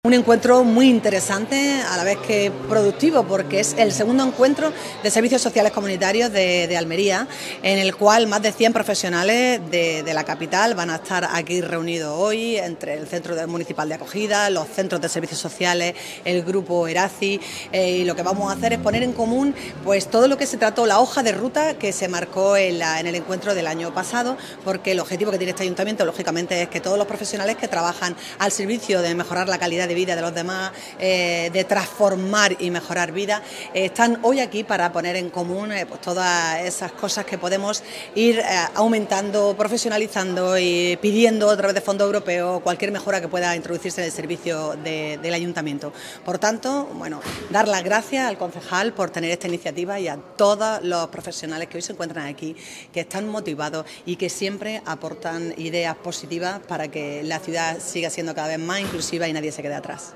María del Mar Vázquez inaugura el II Encuentro de Servicios Sociales Comunitarios celebrado en el Espacio Alma en el que han participado un centenar de trabajadores sociales del Ayuntamiento